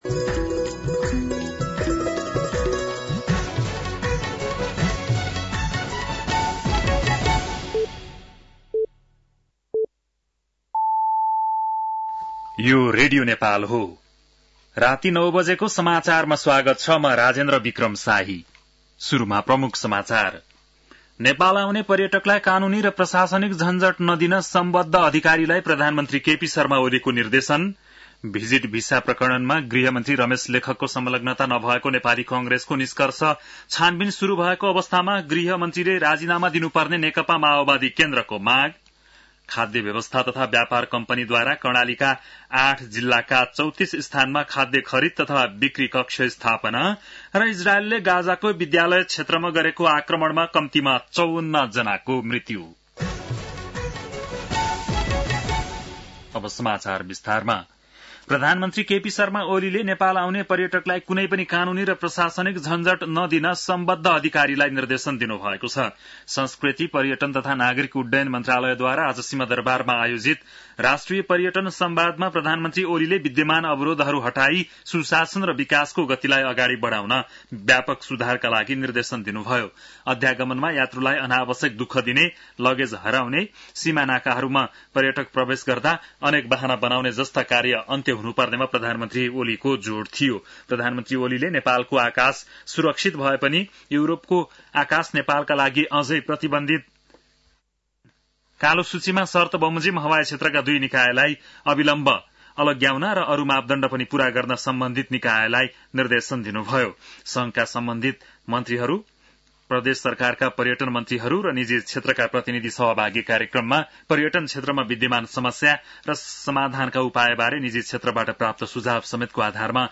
बेलुकी ९ बजेको नेपाली समाचार : १२ जेठ , २०८२
9-PM-Nepali-NEWS-1-3.mp3